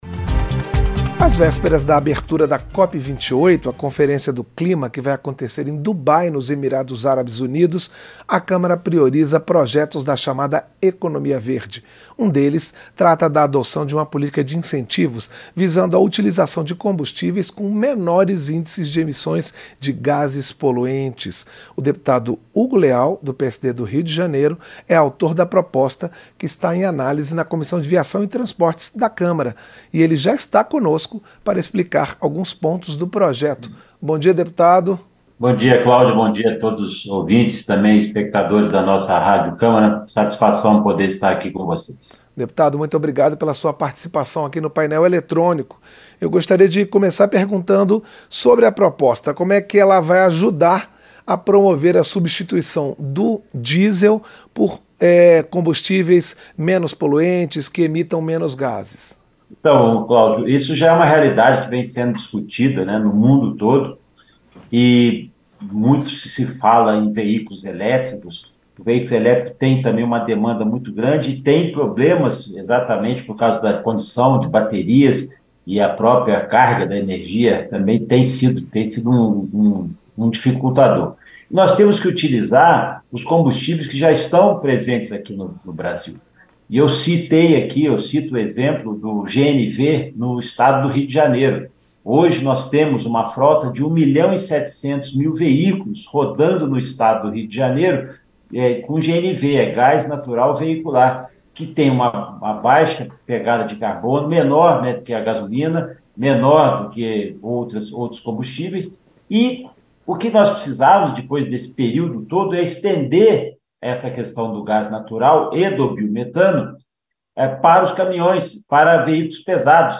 Entrevista - Dep. Hugo Leal (PSD-RJ)